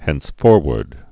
(hĕns-fôrwərd)